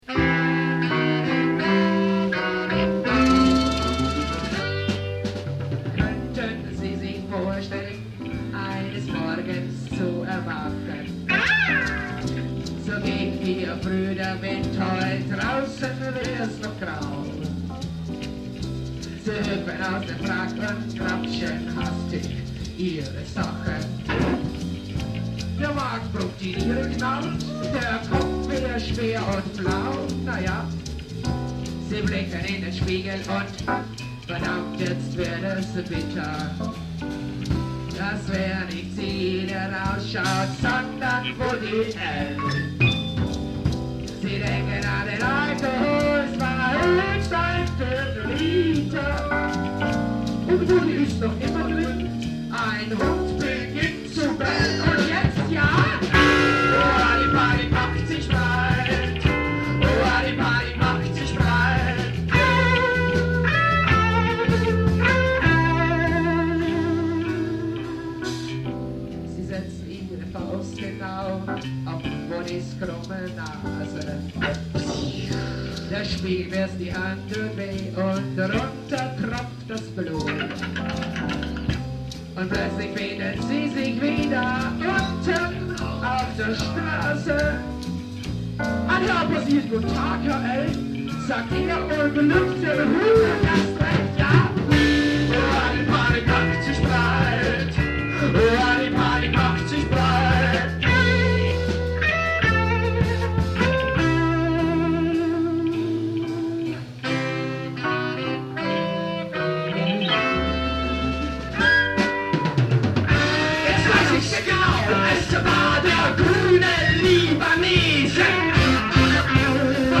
( recorded LIVE - Metropol  28.April 1978 )